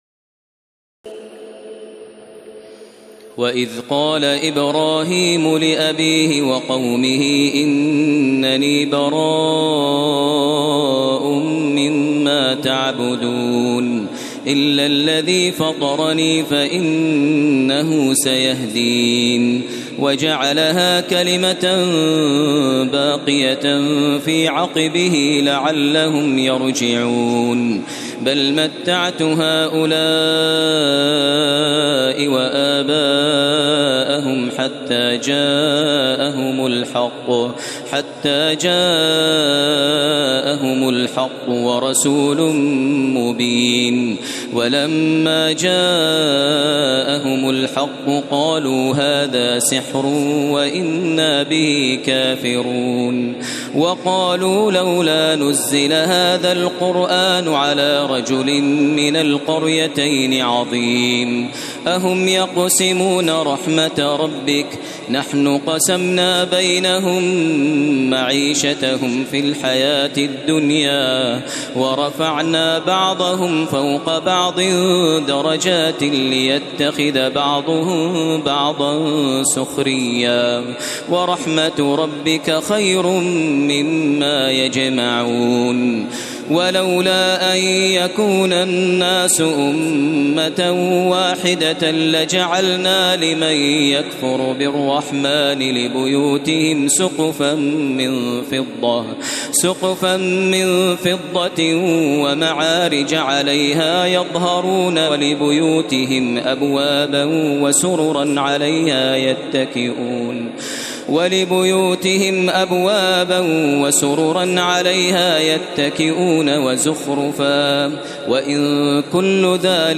تراويح ليلة 24 رمضان 1432هـ من سور الزخرف (26-89) والدخان و الجاثية Taraweeh 24 st night Ramadan 1432H from Surah Az-Zukhruf and Ad-Dukhaan and Al-Jaathiya > تراويح الحرم المكي عام 1432 🕋 > التراويح - تلاوات الحرمين